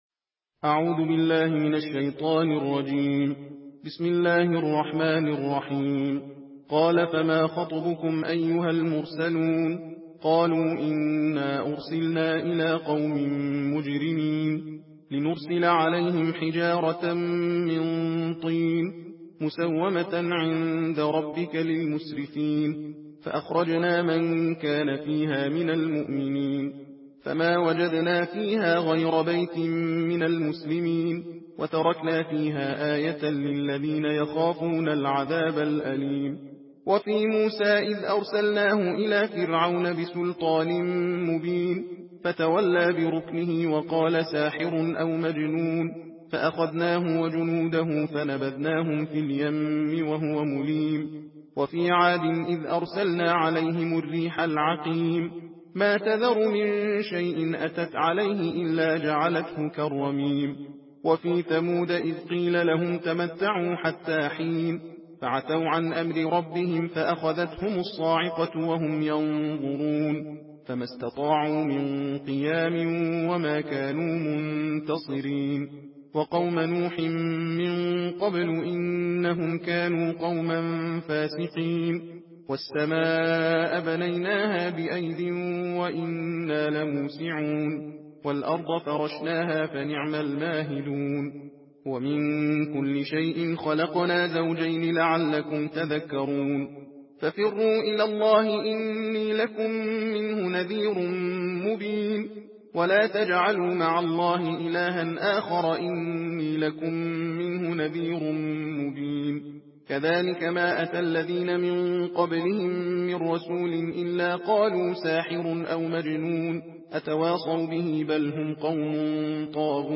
تندخوانی جزء بیست و هفتم قرآن کریم - مشرق نیوز
صوت/ تندخوانی جزء بیست و هفتم قرآن کریم